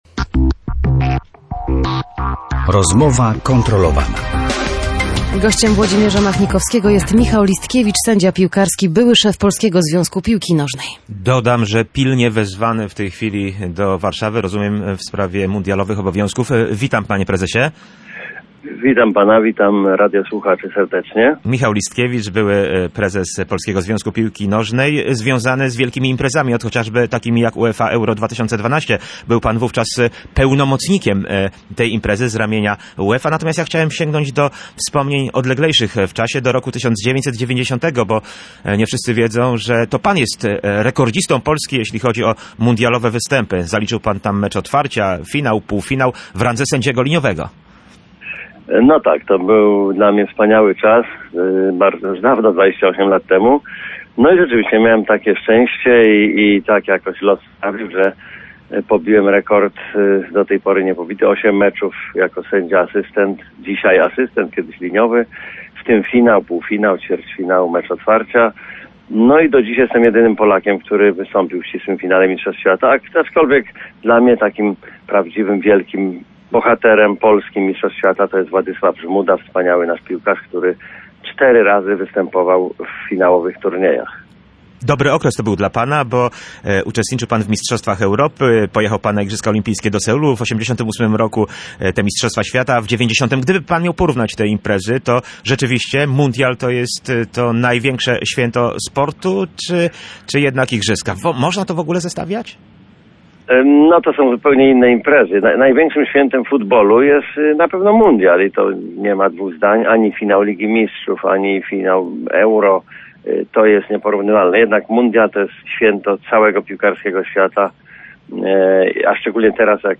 Na pytanie, jak daleko w rozpoczynającym się mundialu zajdzie Polska, nasz gość odpowiedział krótko.